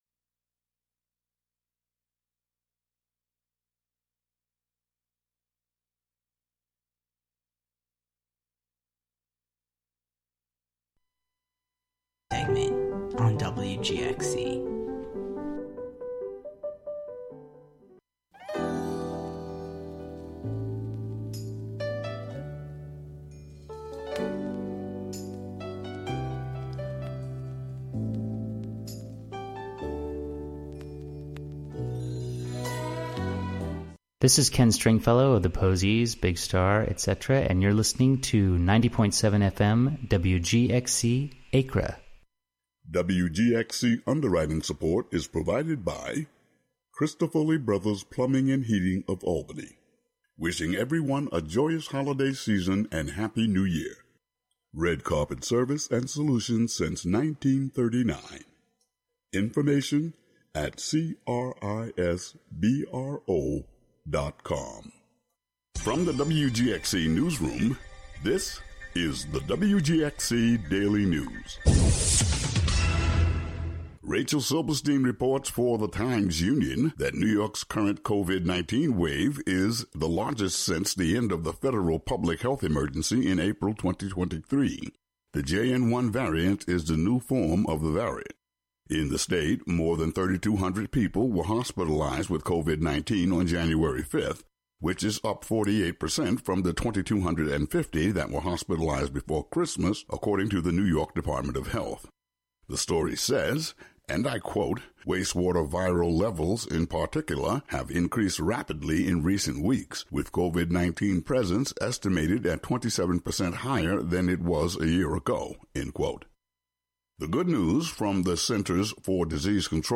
presented here as a two-hour block of psychedelic seep.
Broadcast from Hudson.